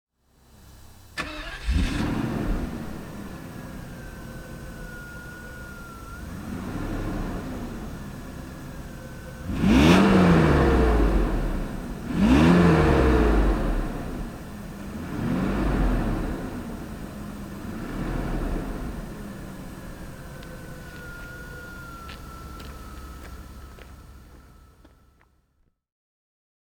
Mercedes-Benz 190 E 2.6 (1987) - Starten und Leerlauf